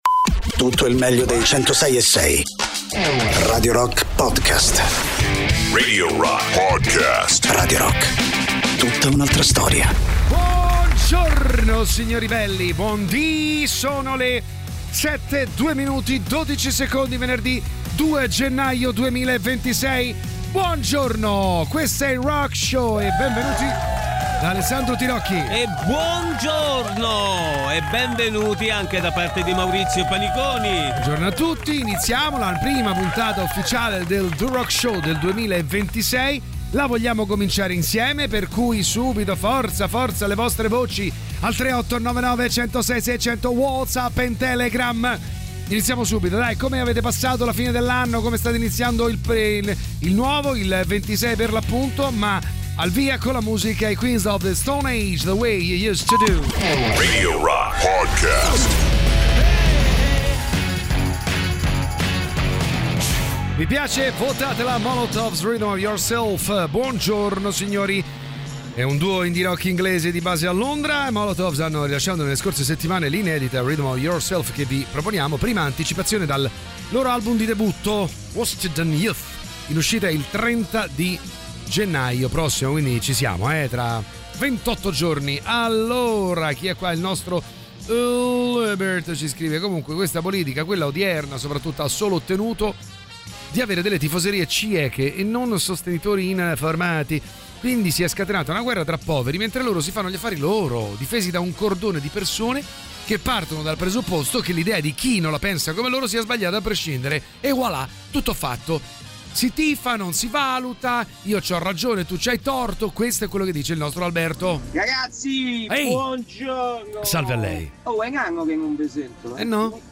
Installez-vous confortablement, montez le volume, et préparez-vous à une dernière virée mémorable 🎶 Last Ride, c'est votre rendez-vous rock à ne pas manquer !